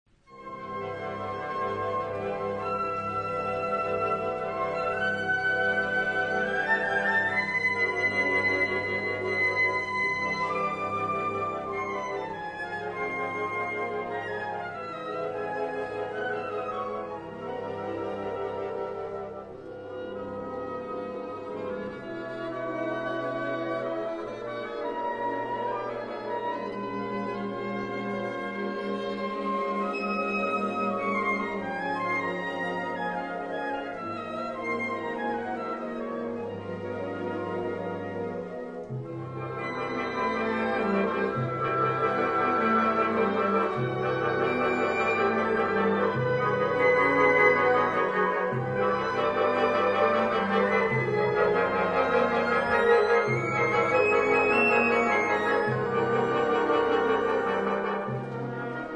Catégorie Harmonie/Fanfare/Brass-band
Sous-catégorie Musique originale contemporaine
Instrumentation Ha (orchestre d'harmonie)